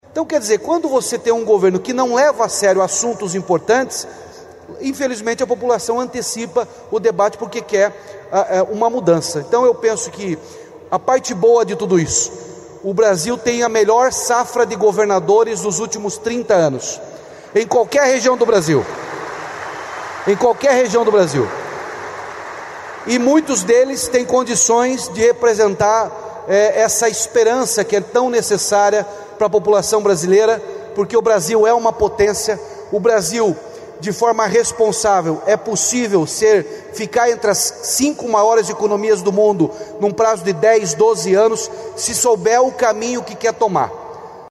O governador do Paraná, Ratinho Junior (PSD), participou, neste sábado (26), de um painel durante o Expert XP, evento promovido pela XP Investimentos em São Paulo.